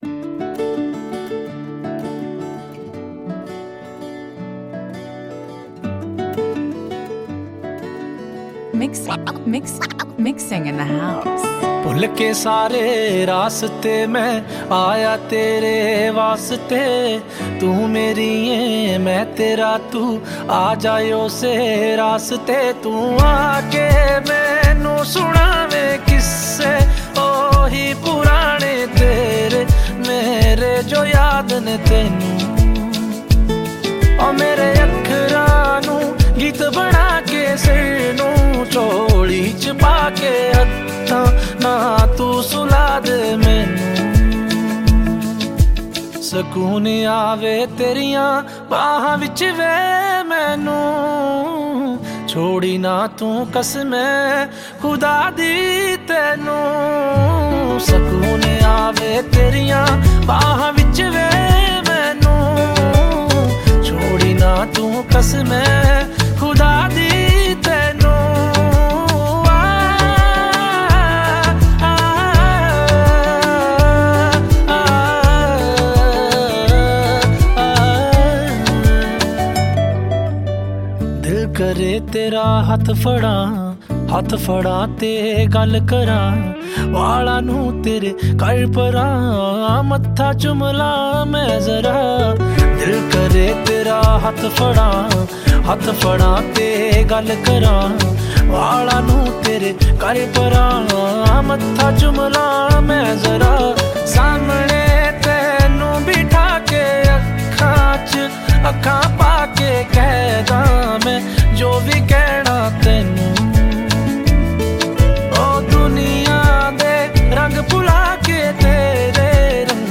Category: Punjabi Singles